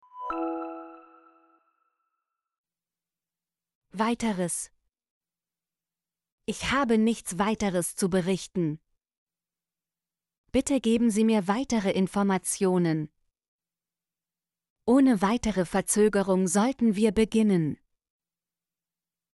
weiteres - Example Sentences & Pronunciation, German Frequency List